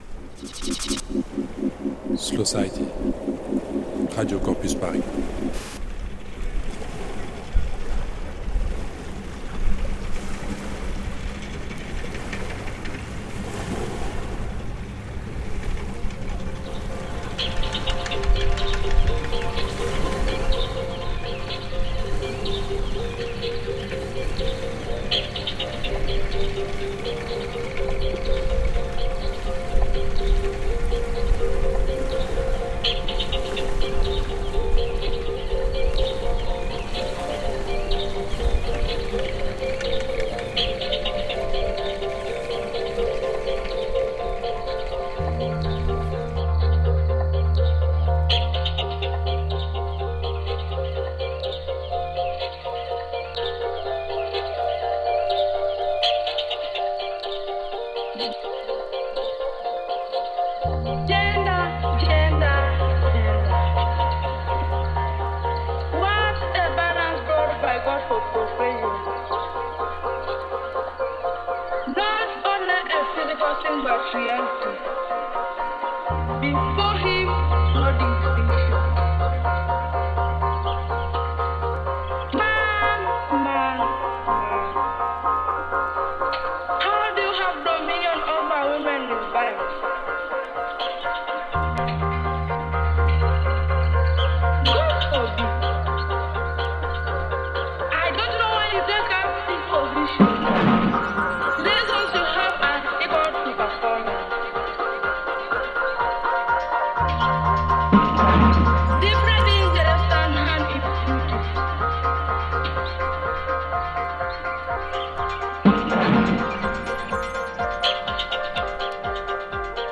une heure de délicieuse deep-house